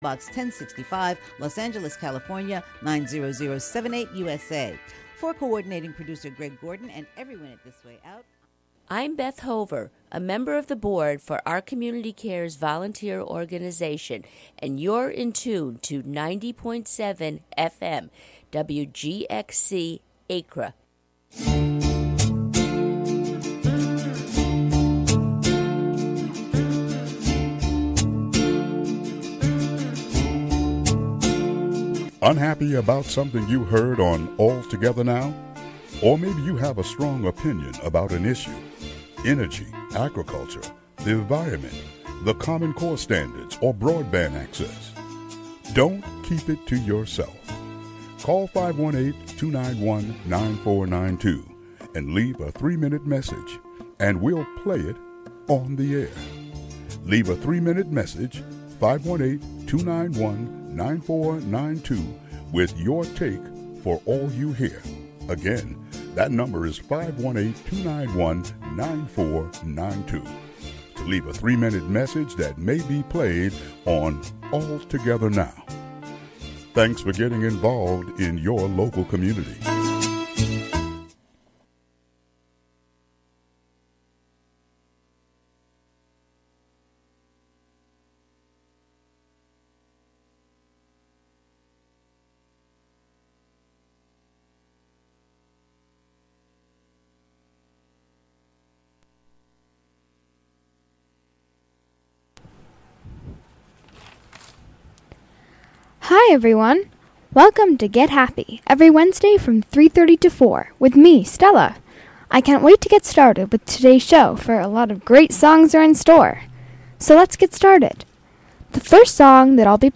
plays a half hour of 1930s and 1940s music, with an occasional foray into other genres.